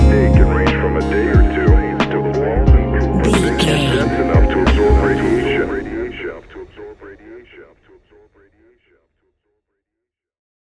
Ionian/Major
C♭
chilled
laid back
Lounge
sparse
new age
chilled electronica
ambient
atmospheric